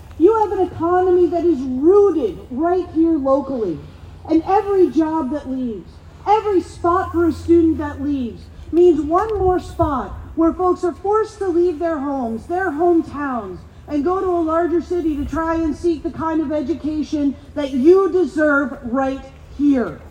With chants of ‘our college, our future,’ a large number of faculty, support staff, students and union supporters rallied at Loyalist College Wednesday afternoon.